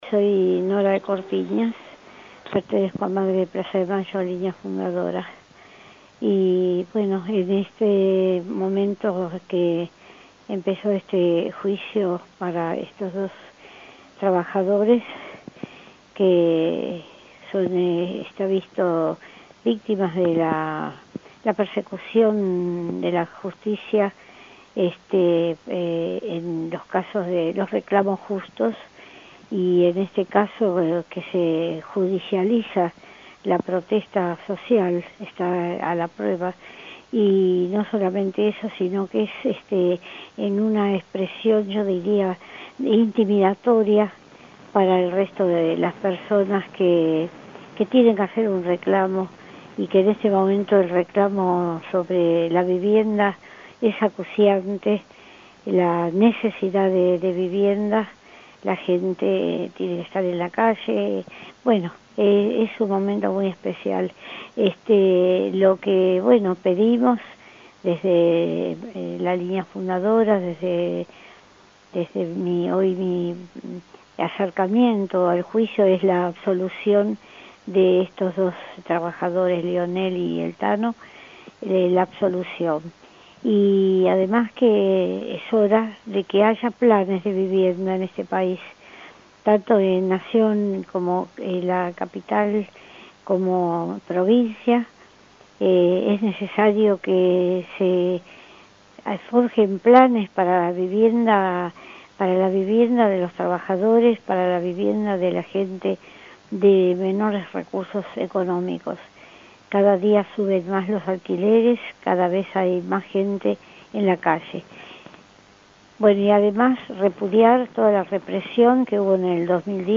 Entrevista a Nora Cortiñas